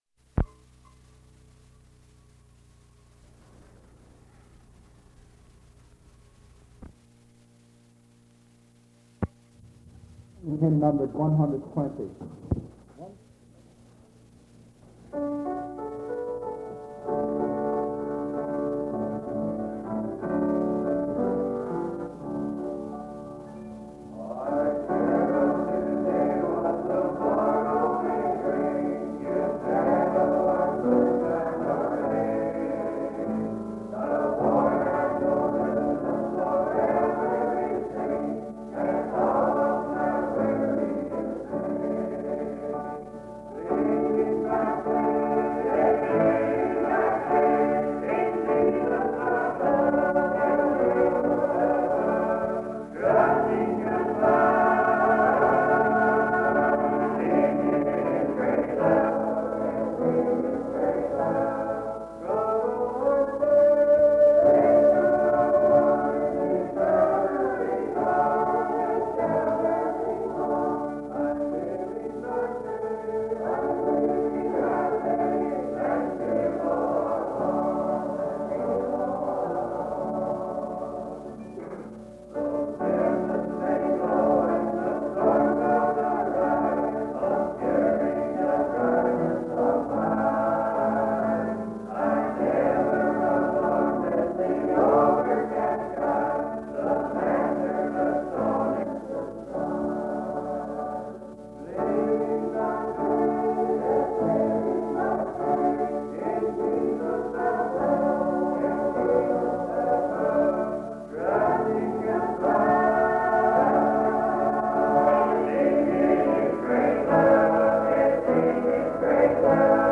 Service from Big Springs Baptist Church in Virginia